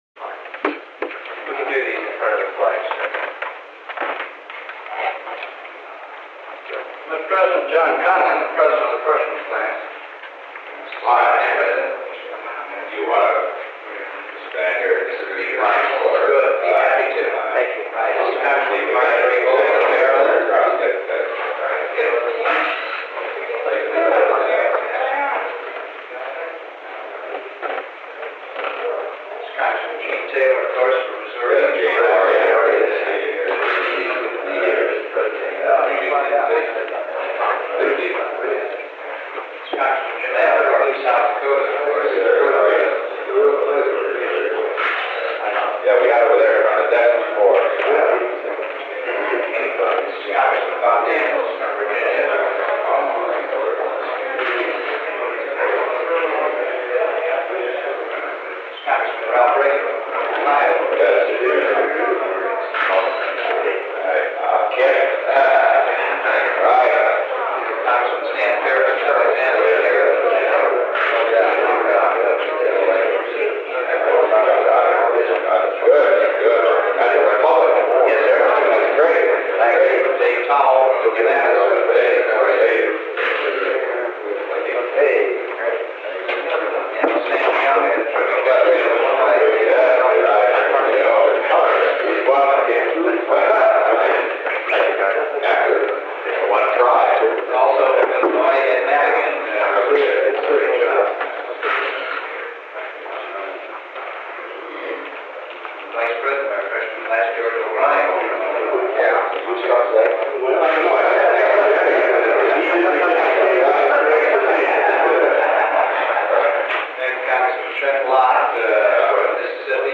Secret White House Tapes | Richard M. Nixon Presidency